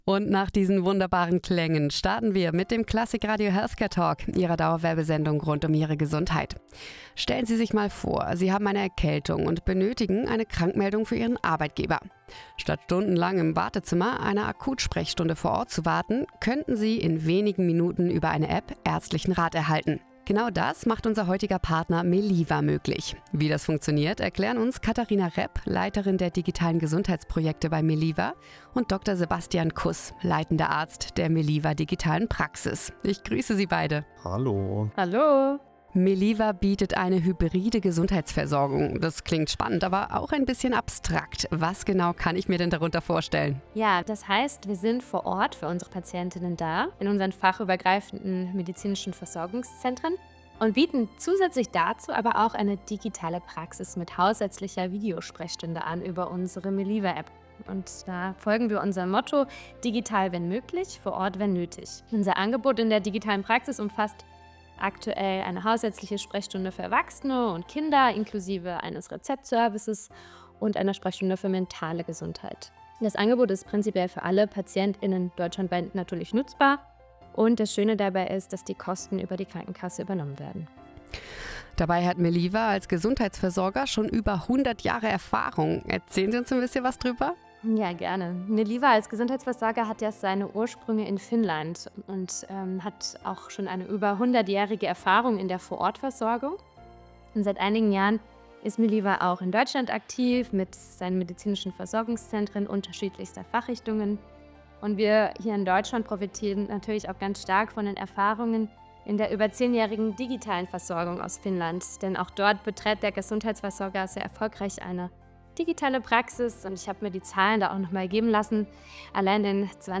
Our interview on Klassik Radio
Meliva-Healthcare-Talk-I.mp3